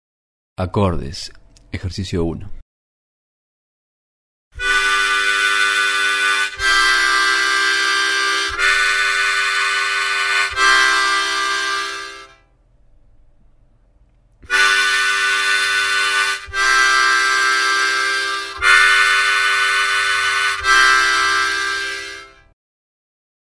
7 Ejercicios para práctica de acordes
Acordes-Ejercicio-1.mp3